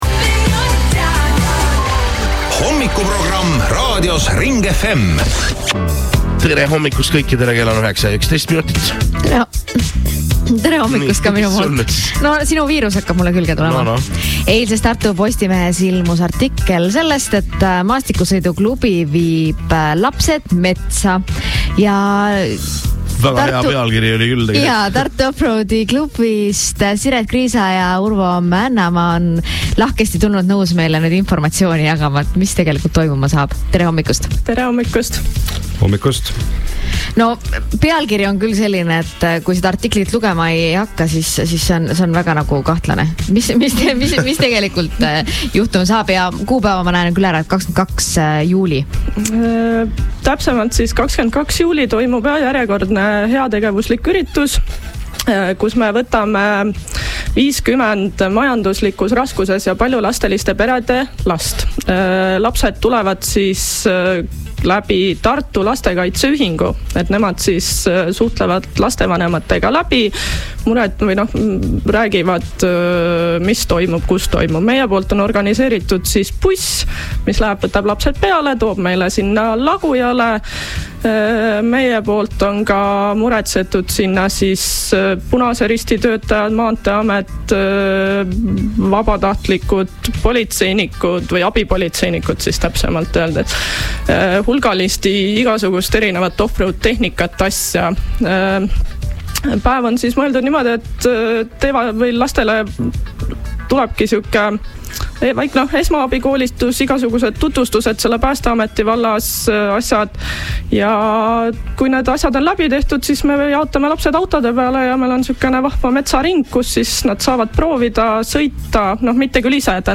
Kuula ka Ring FM-i hommikusaadet ürituse kohta: